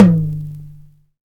• Large Room Tom Drum One Shot D Key 11.wav
Royality free tom drum single hit tuned to the D note. Loudest frequency: 307Hz
large-room-tom-drum-one-shot-d-key-11-2lK.wav